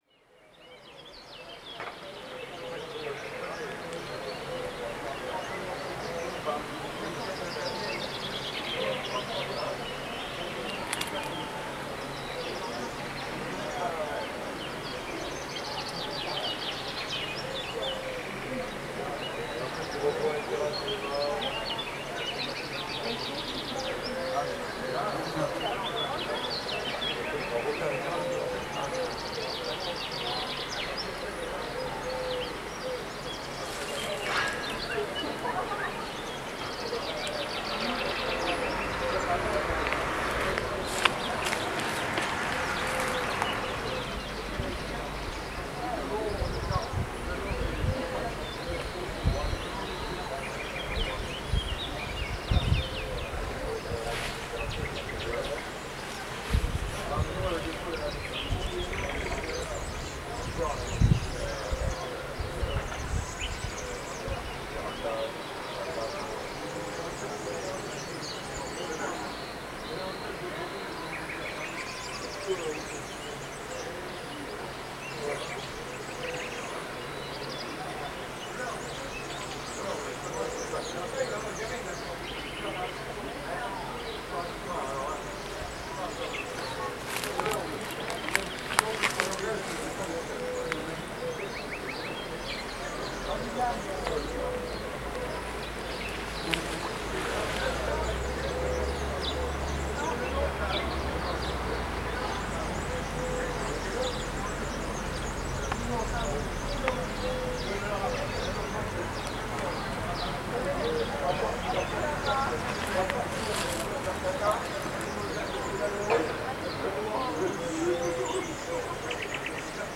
Summer Sounds (Evening Thunderstorm) Audio 50